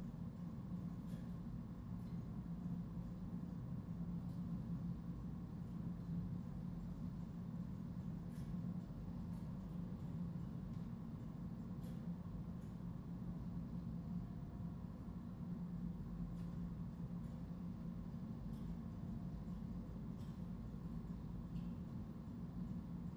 SaSLaW / noiseonly /car.wav
car.wav